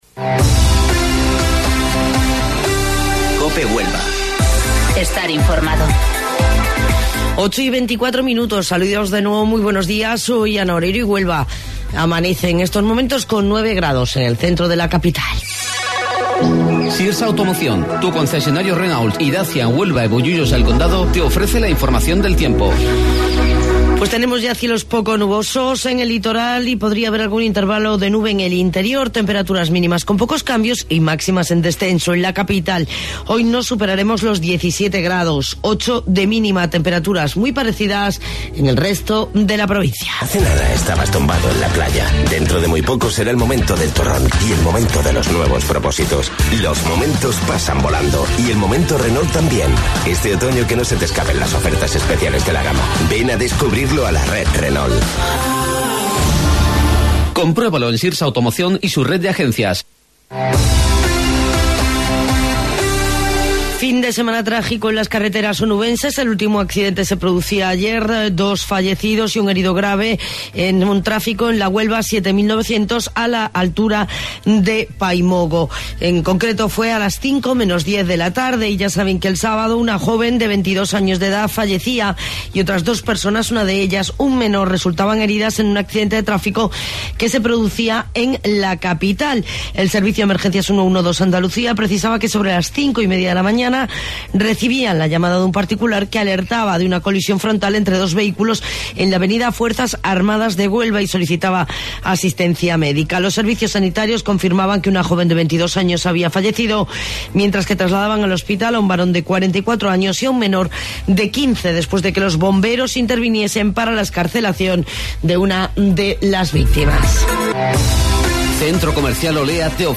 AUDIO: Informativo Local 08:25 del 18 de Noviembre